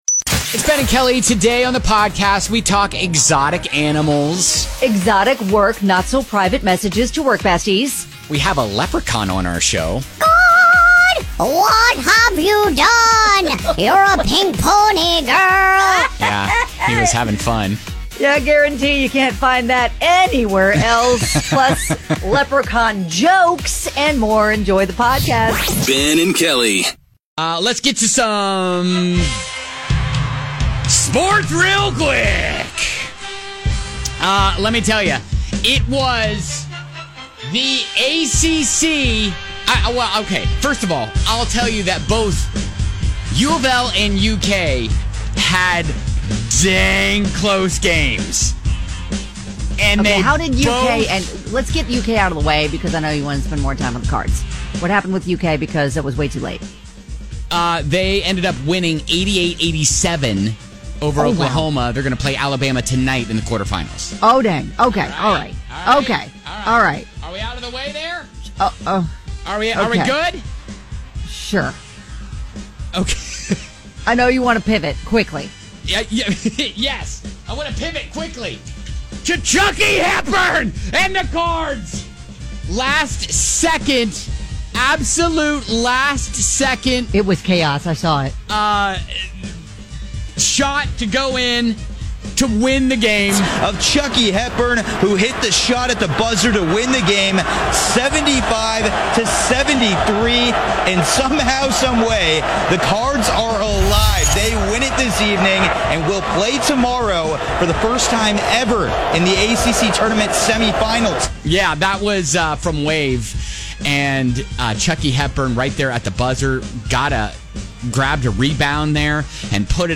We found a magical (and talented) Leprechaun to sing some pop hits...can you guess which ones?